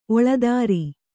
♪ oḷadāri